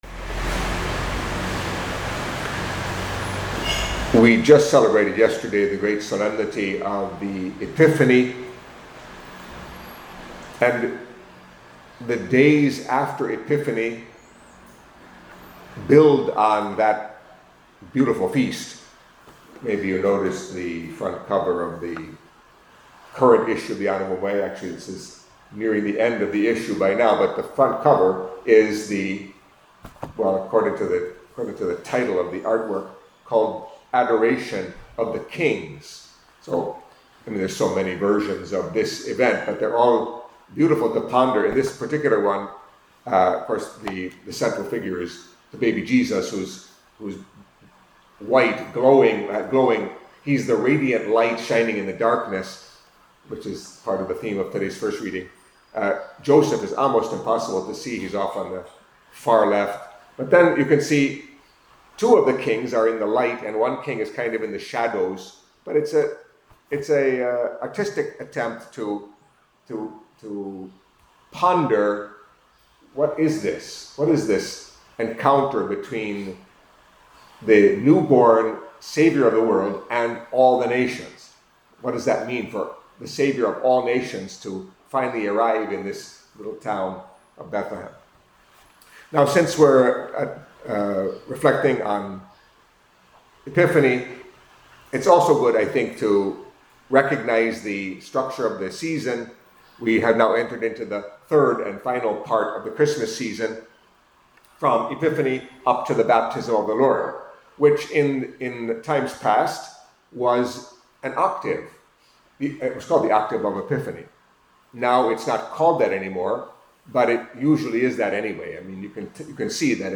Catholic Mass homily for Monday after Epiphany